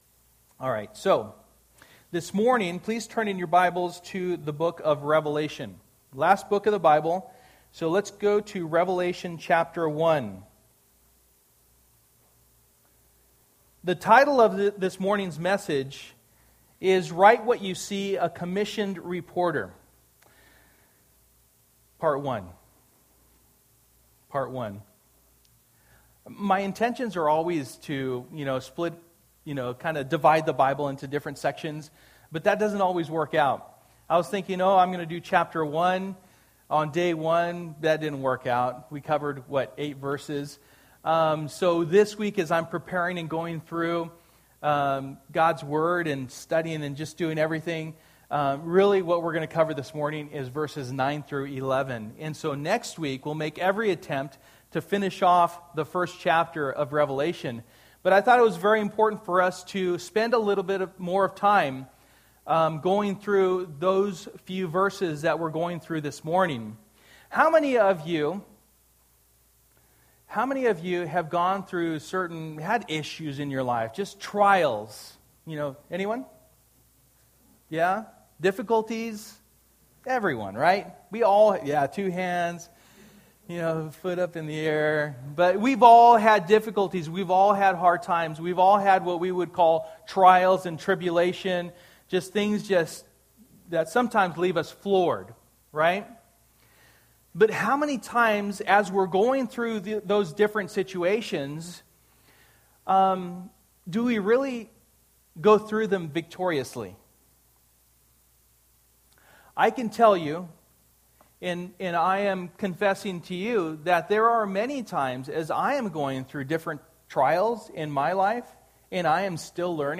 Passage: Revelation 1:9-11 Service: Sunday Morning